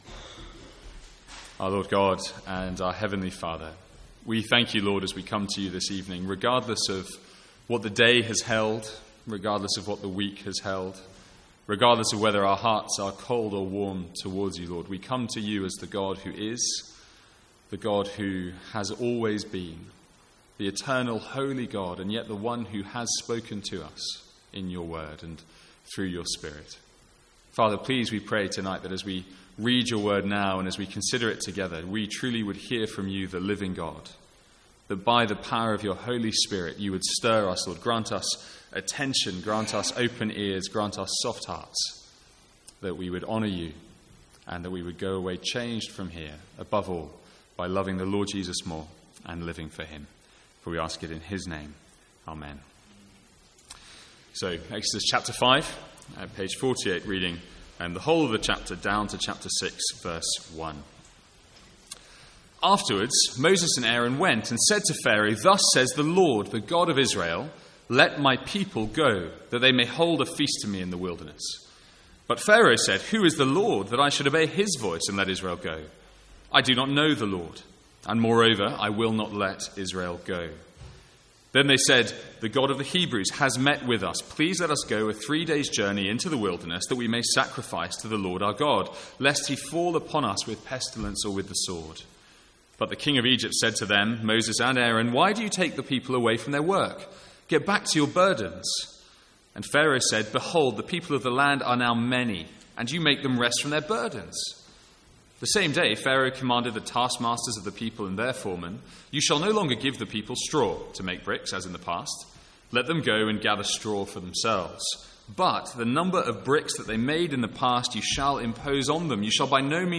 Sermons | St Andrews Free Church
From the Sunday evening series in Exodus.